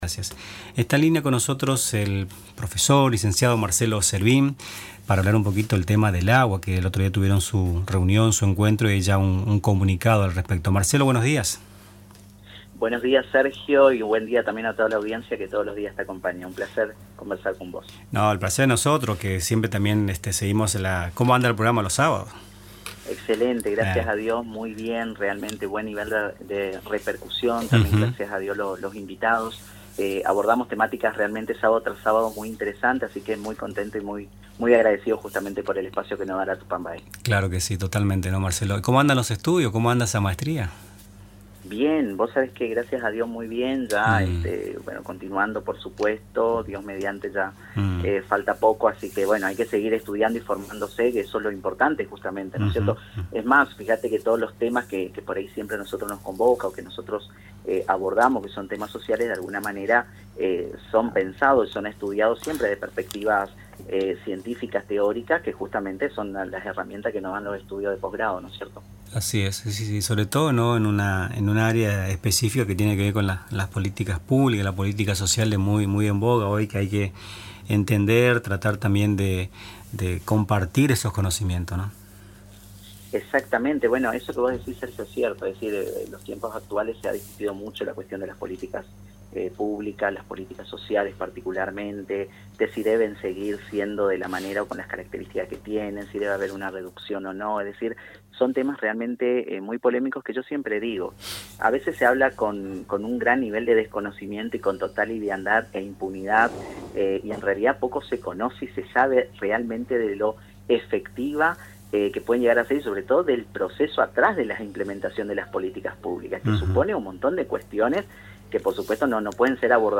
En comunicación telefónica con Nuestras Mañanas